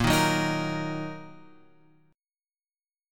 A#sus4#5 chord {x 1 1 3 4 2} chord